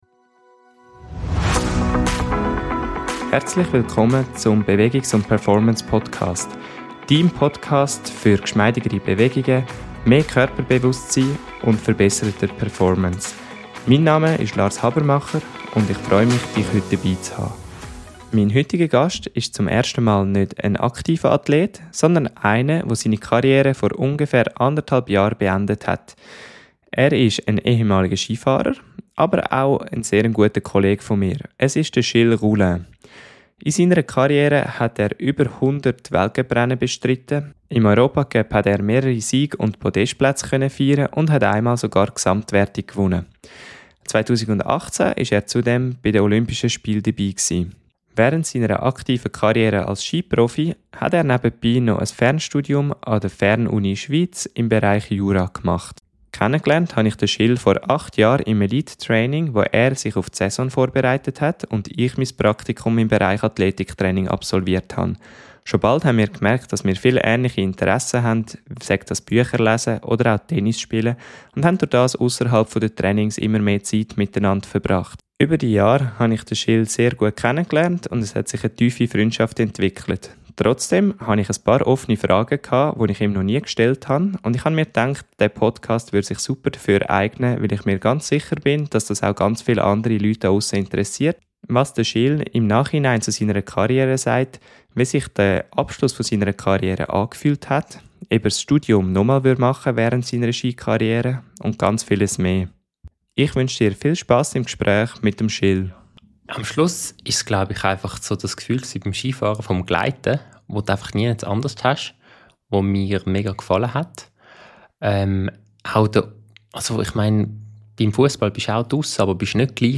Ein ehrliches Gespräch über Loslassen, Neuanfänge und was vom Sport für immer bleibt.